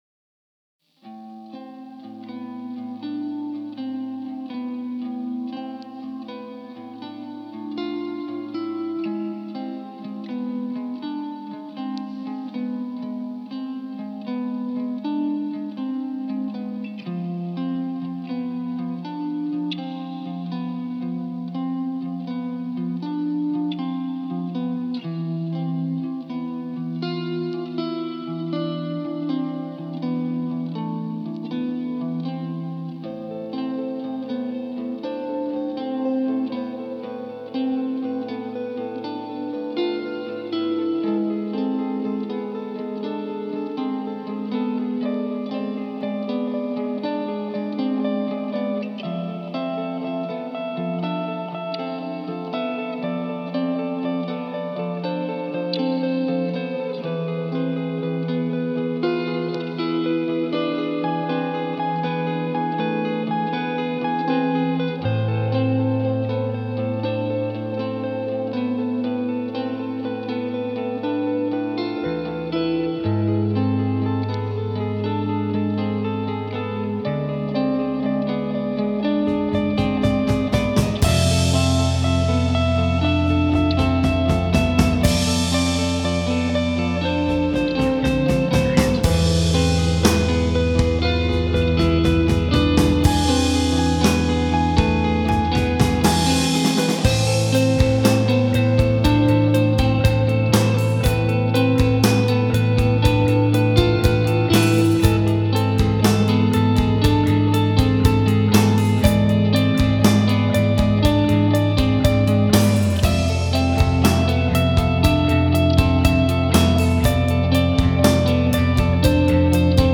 Коллеги прошу мнения (Post-Rock)
Задача: получить пост-рок, с "невылизанным" звуком, передать характер "гаражности" даже, инди-все дела, сохранить макродинамику. Пришлось особенно побиться с барабанами: барабанщик просто от души молотил в хайхэт, и пришлось его гасить всеми способами даже из оверов (не говоря о рабочем).